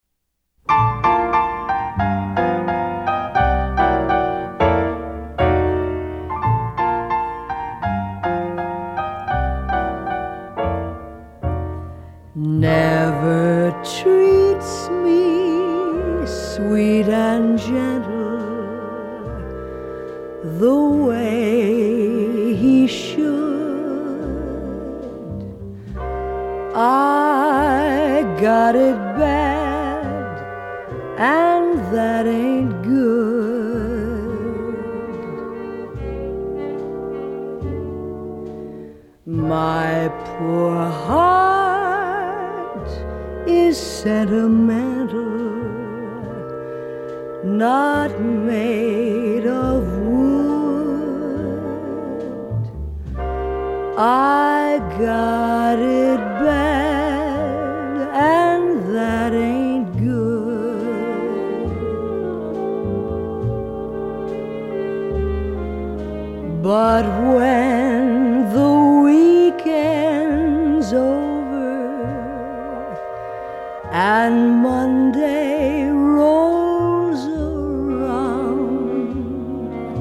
頂尖的樂團、一流的編曲，加上動人的演唱